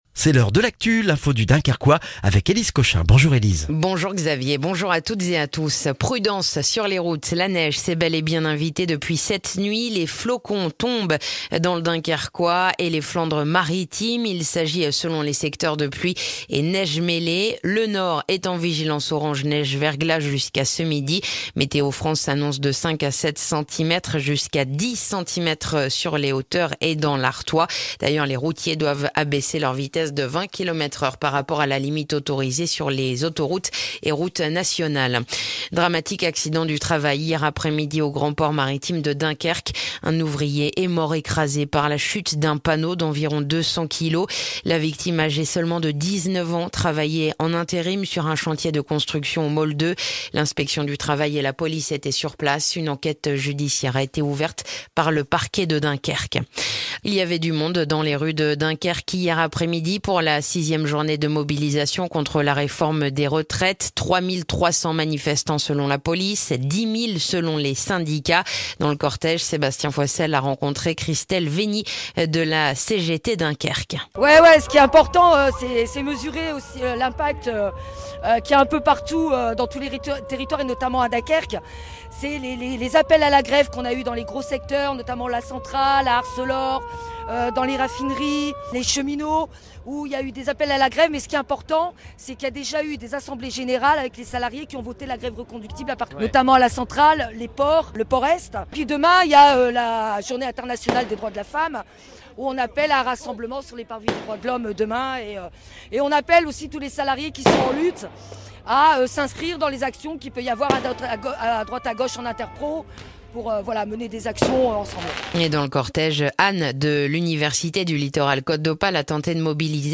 Le journal du mercredi 8 mars dans le dunkerquois